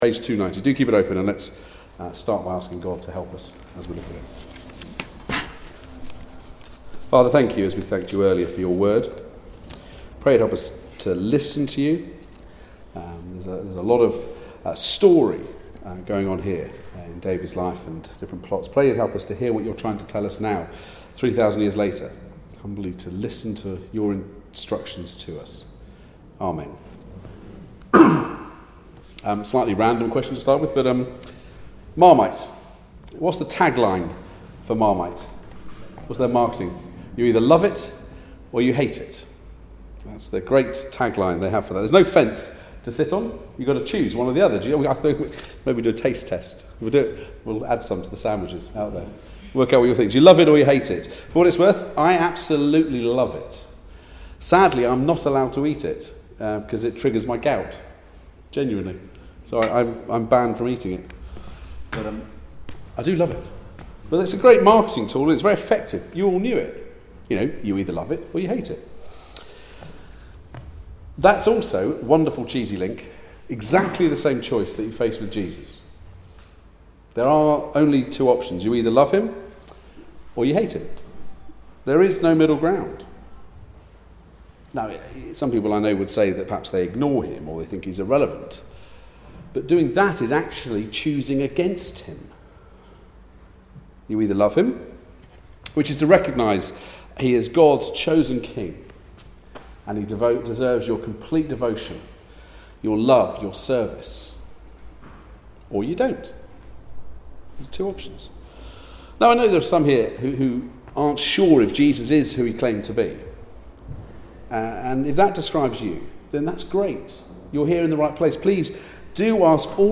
1 Samuel 18-20 Service Type: Weekly Service at 4pm Bible Text
2Jul17-Sermon.mp3